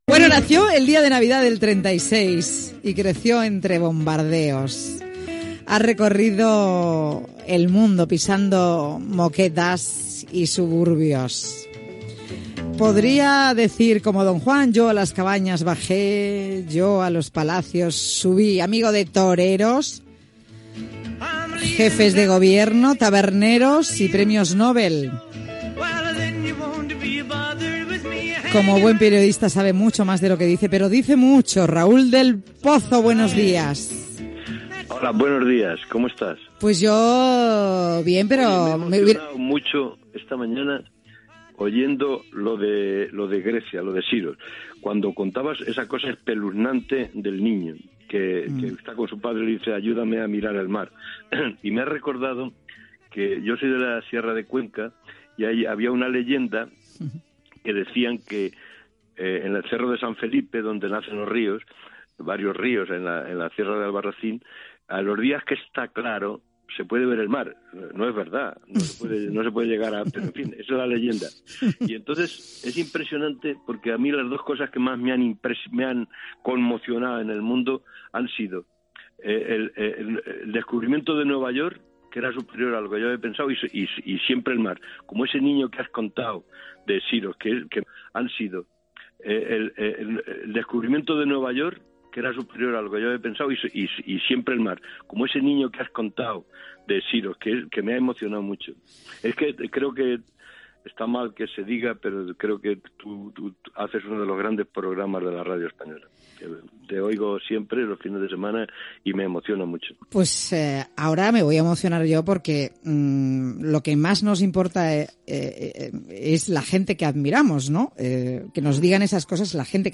Fragment d'una entrevista al periodista Raúl del Pozo.
Entreteniment
FM